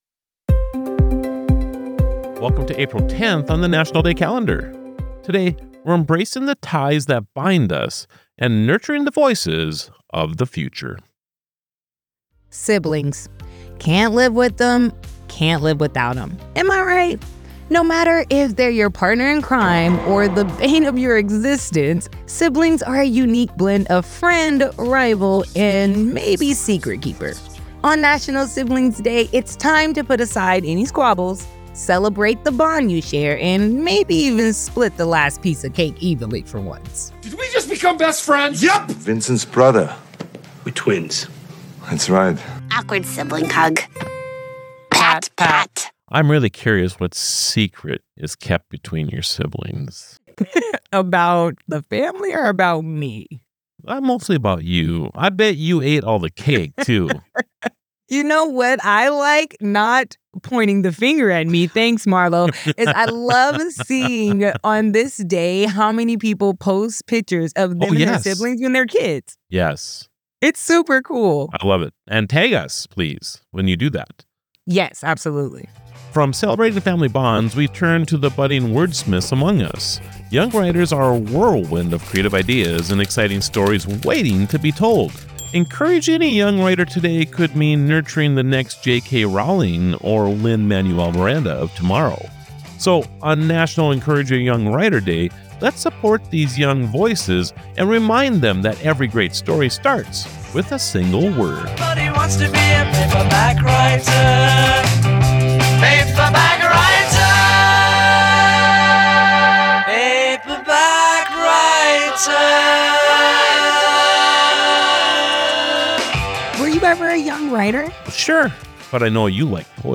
Destination Celebration is a dynamic, ready-to-air 2.5-minute program spotlighting a couple of the day’s fun and engaging National Day celebrations.
Many stations find local sponsors eager to align with this upbeat daily feature.